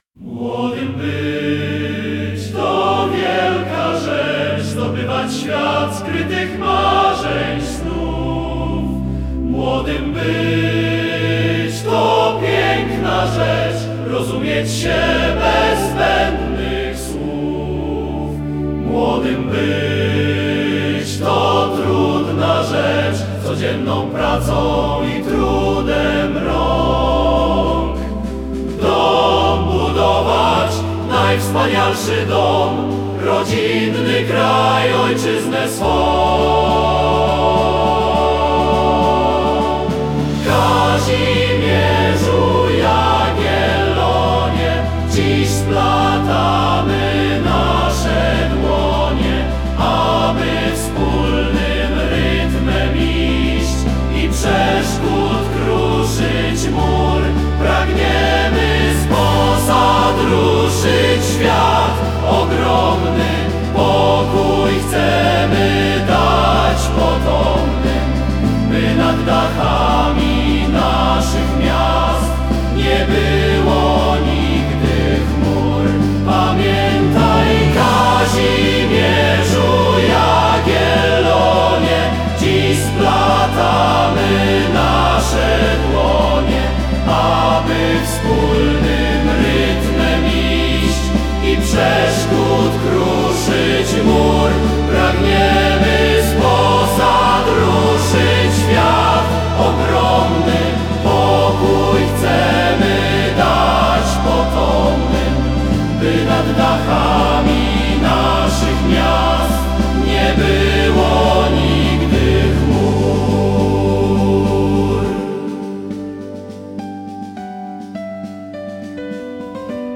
05-hymn-ii-lo-orkiestra-i-chor-wojskowy.mp3